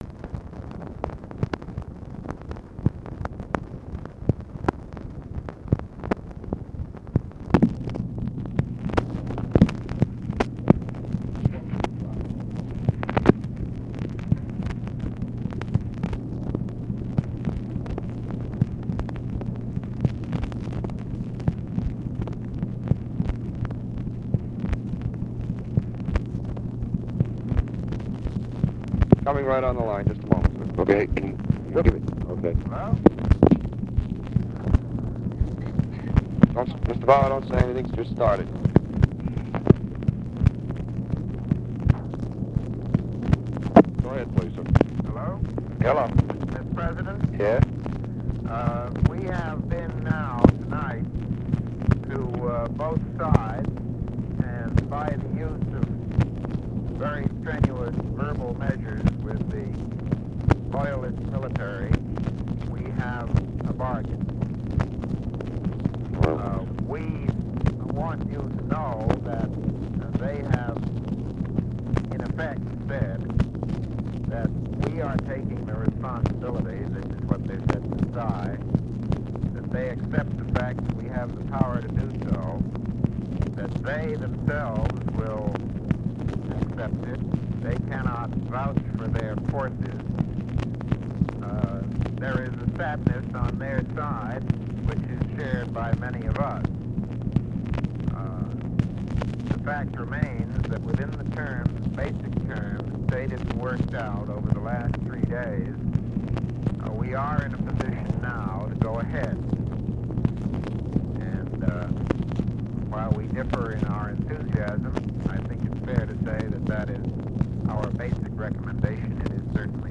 Conversation with MCGEORGE BUNDY, ABE FORTAS and THOMAS MANN, May 18, 1965
Secret White House Tapes